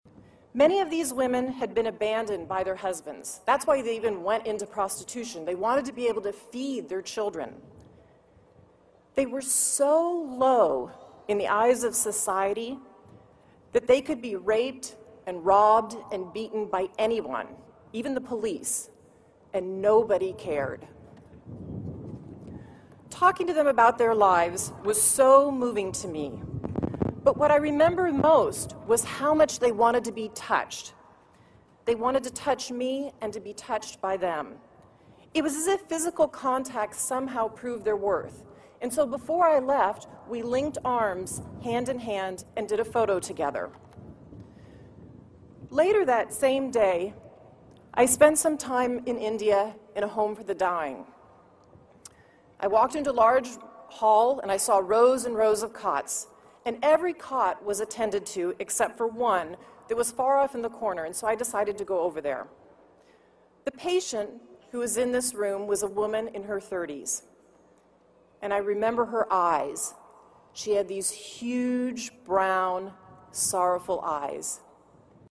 公众人物毕业演讲第30期:比尔盖茨夫妇于斯坦福大学(11) 听力文件下载—在线英语听力室